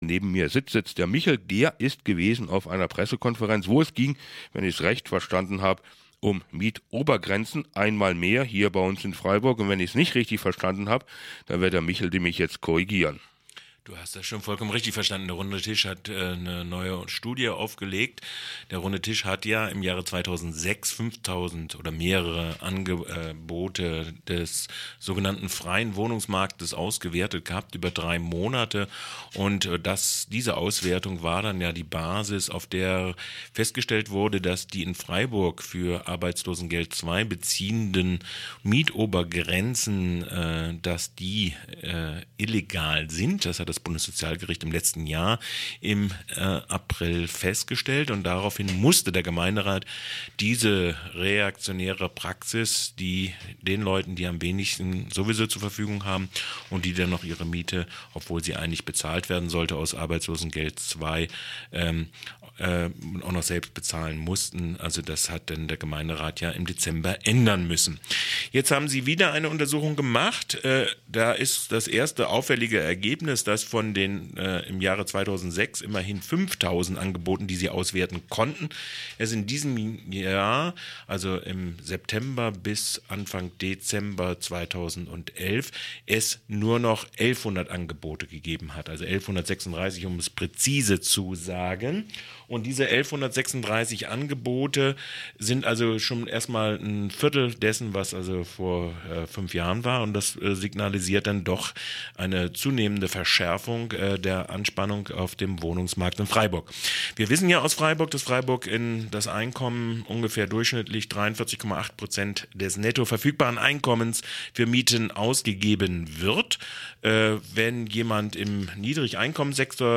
Bericht von PK des runden Tisches zu den Auswirkungen der Hartz-Gesetze